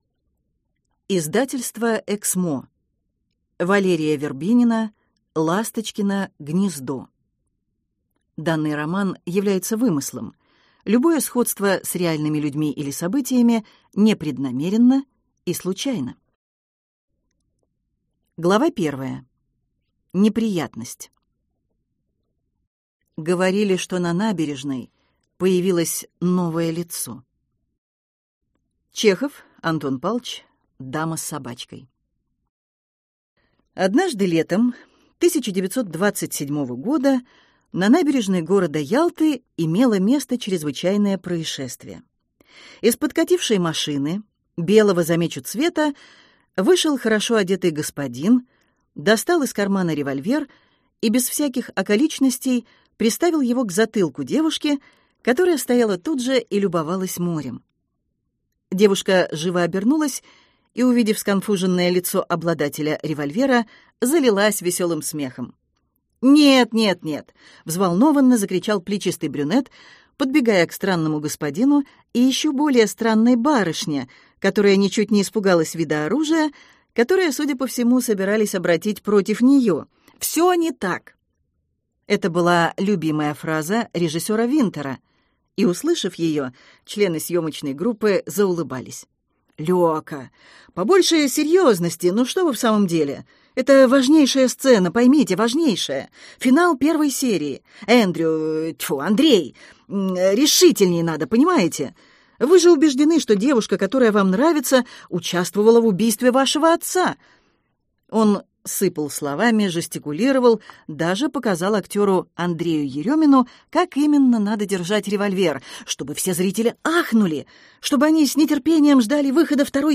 Аудиокнига Ласточкино гнездо | Библиотека аудиокниг
Прослушать и бесплатно скачать фрагмент аудиокниги